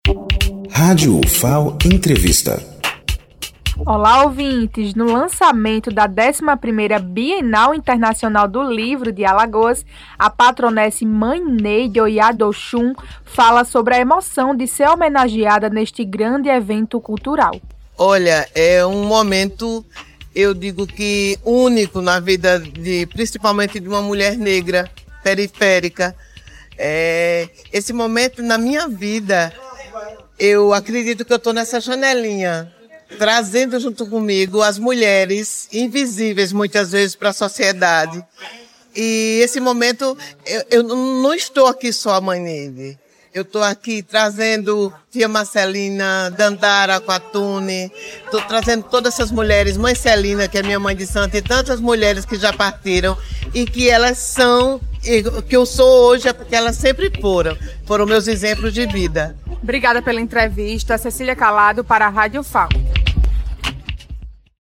Café de lançamento da 11ª Bienal Internacional do Livro de Alagoas
Entrevista